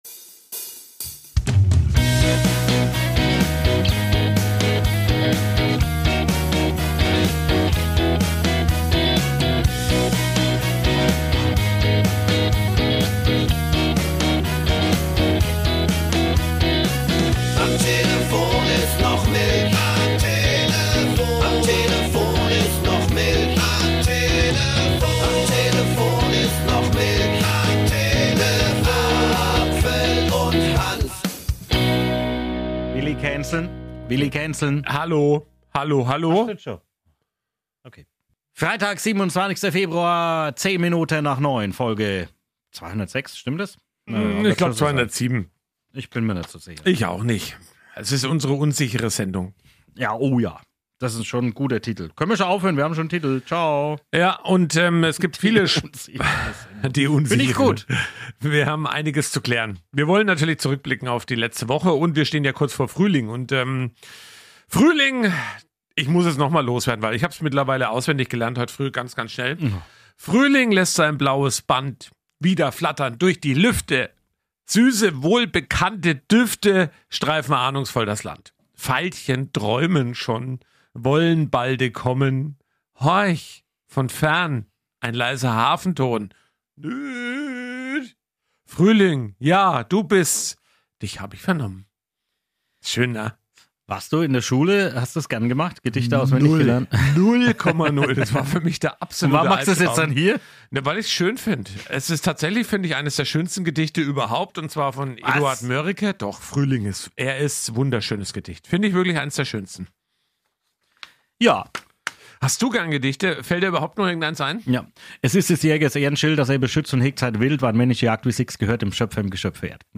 Dazu gibt es viele Berichte und Interviews